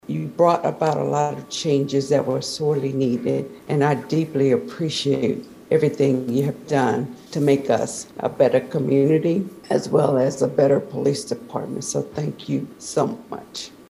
After three years and one month leading the Riley County Police Department, Riley County Police Department Director Dennis Butler sat in on his final Law Board meeting Tuesday afternoon at Manhattan City Hall.